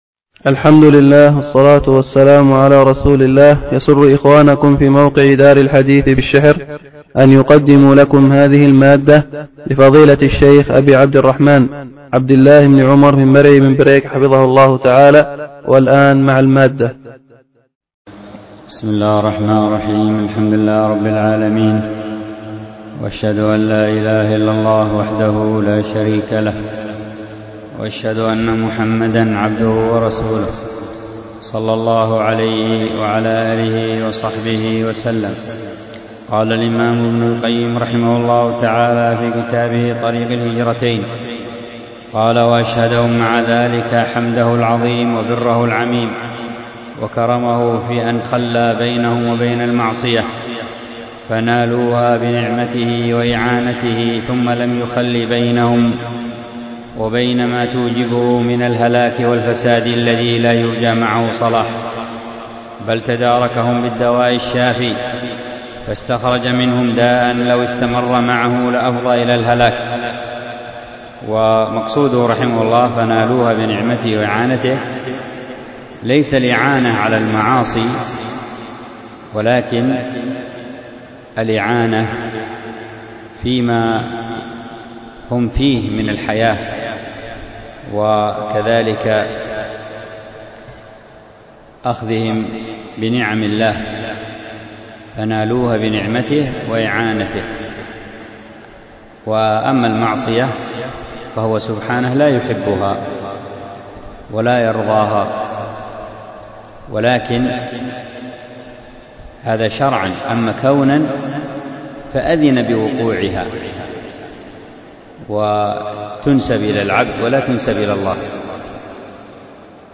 الدرس في التعليقات على كتاب الأدب المفرد 245، ألقاها